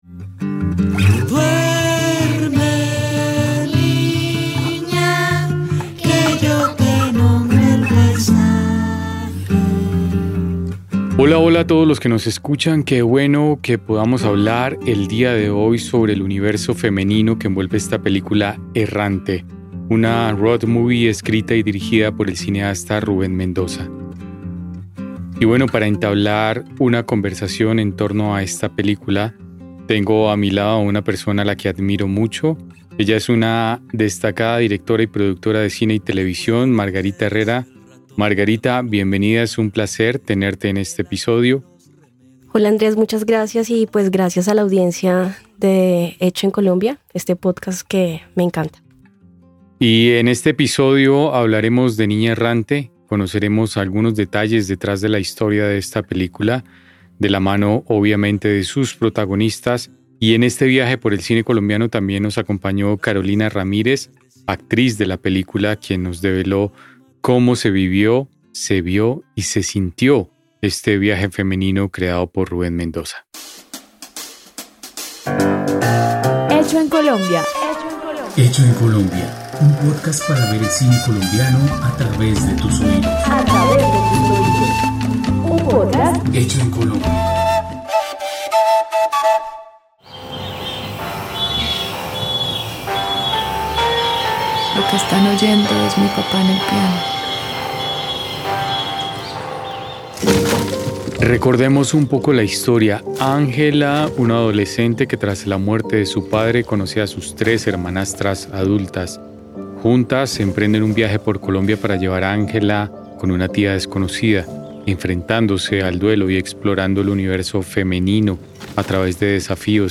Estudio de grabación: Radiola Music Estudio.